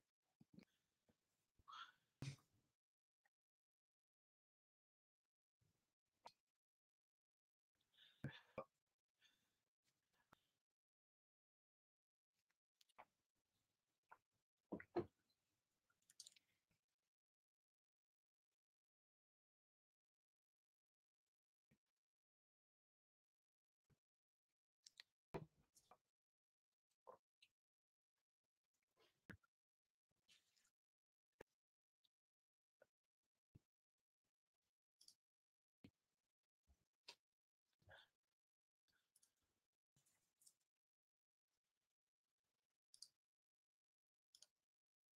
I have provided with a sample for you & each of my fellow members to test the natural noise sample.